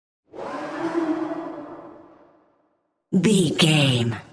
Sound Effects
Atonal
scary
ominous
eerie
Horror Synths